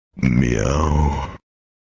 meow1.ogg